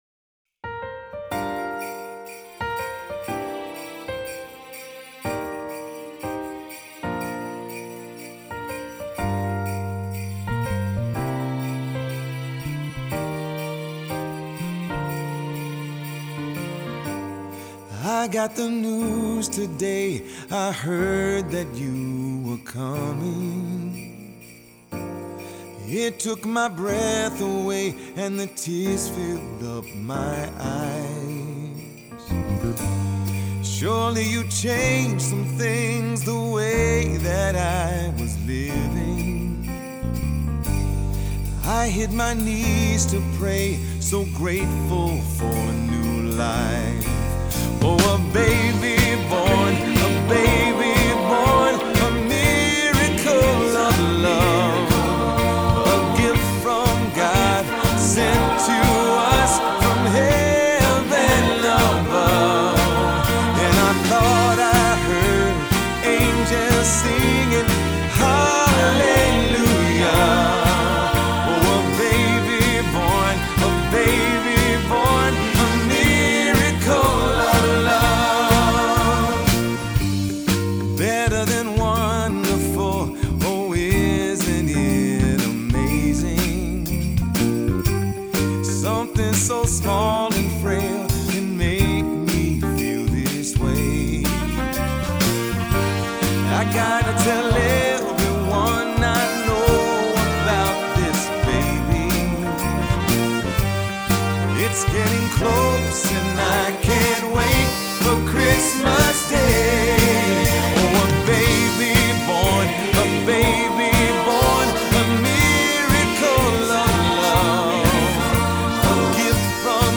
Choral Christmas/Hanukkah
Uplifting and spiritually encouraging
SATB